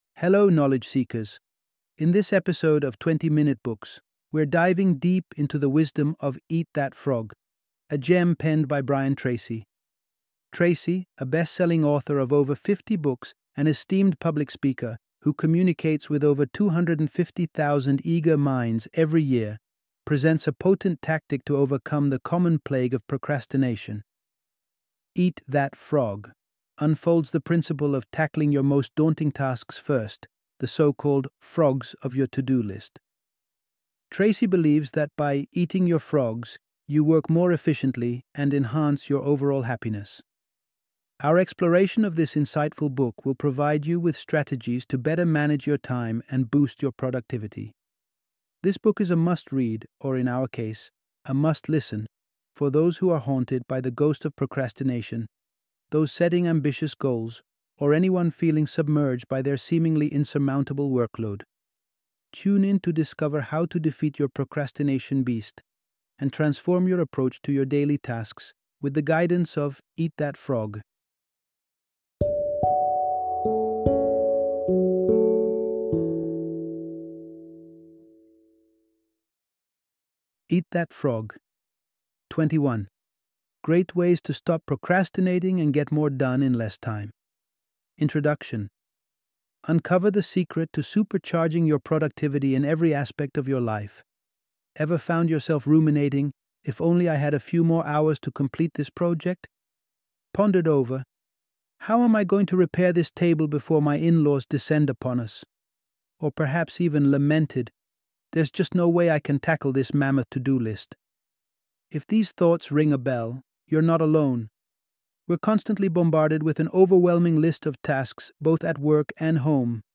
Eat That Frog! - Audiobook Summary